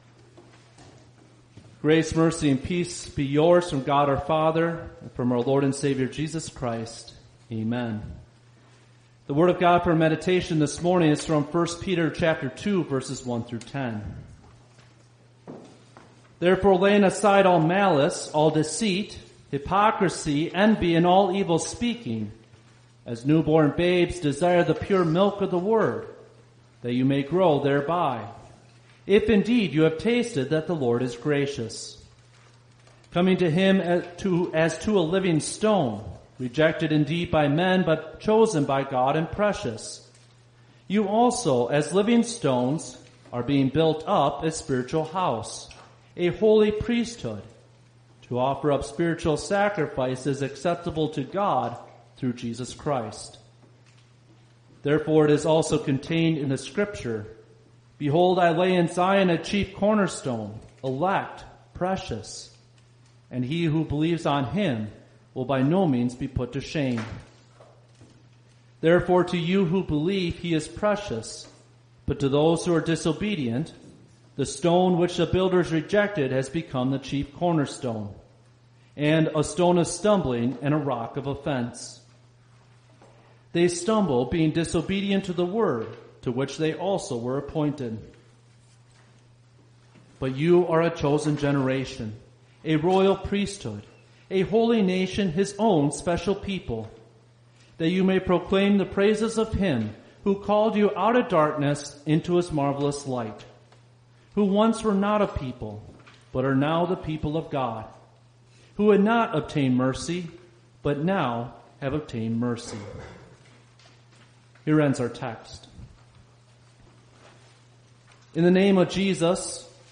Confirmation-Sunday-and-Palm-Sunday.mp3